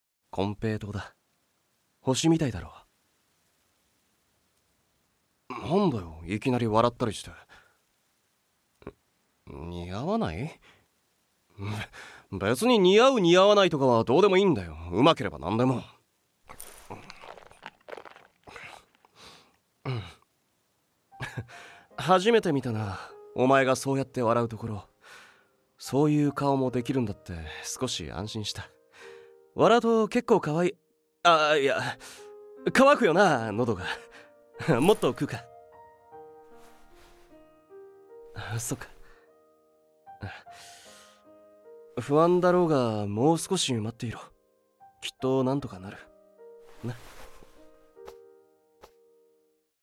天狗ノ契リ サンプルボイス01